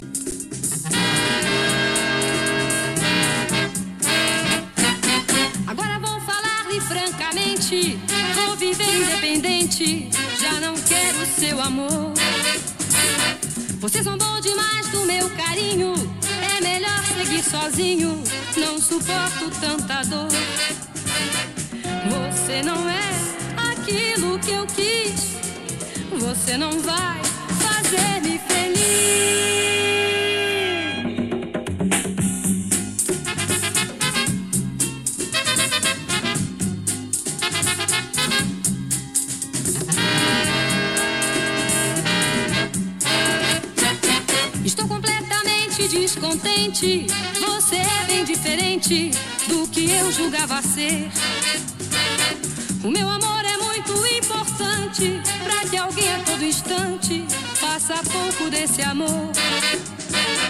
a majestic, horn-infused number laced with emotion